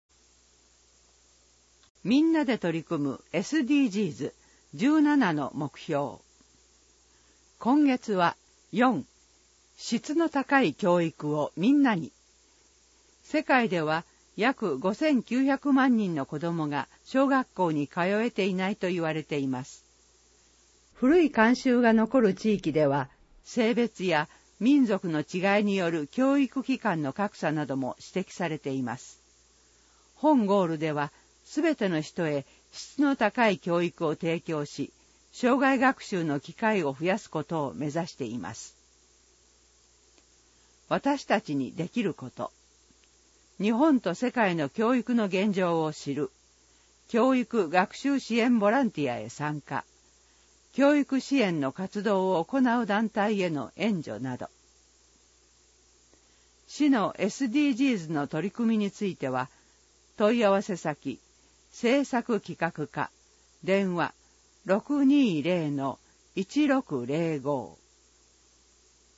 毎月1日発行の広報いばらきの内容を音声で収録した「声の広報いばらき」を聞くことができます。